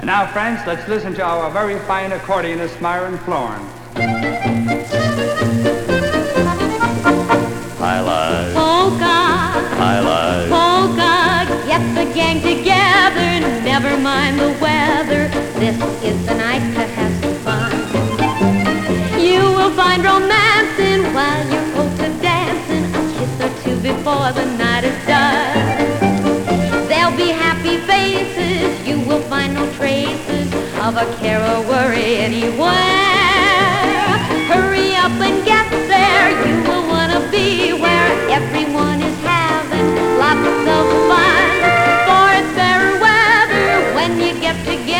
※盤自体に起因するプチプチ音有り
Jazz, Pop, Easy Listening　USA　12inchレコード　33rpm　Mono